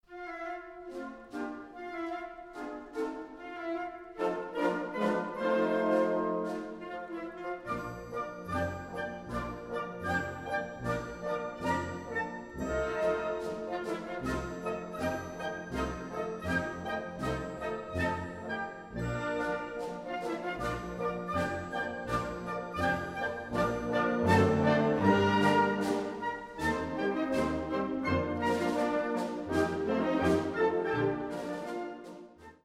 Gattung: Polka Francaise
Besetzung: Blasorchester